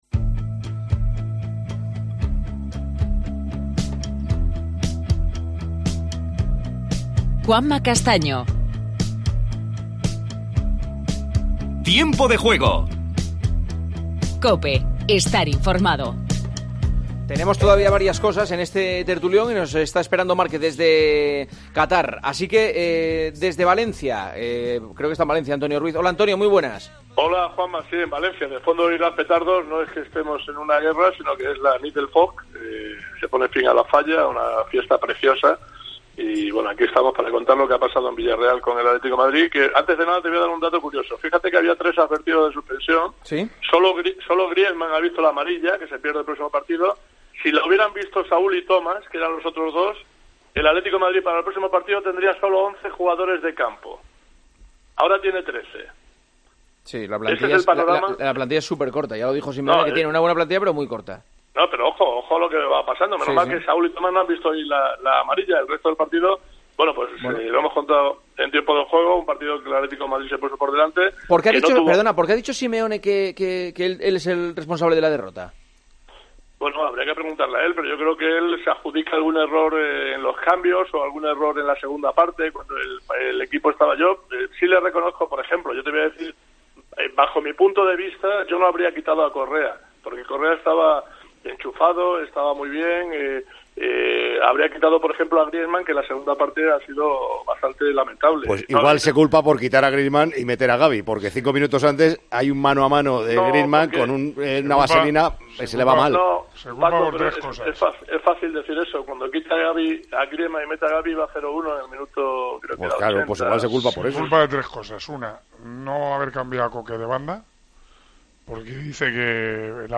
Además, entrevistas a Míchel, entrenador del Rayo Vallecano; y a Marc Márquez, segundo clasificado en el GP de Catar de motociclismo. Resumen de la final de la Copa de España de fútbol sala.